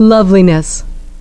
loveliness <)), lovableness <)), we see that the stress is constant on the first syllable.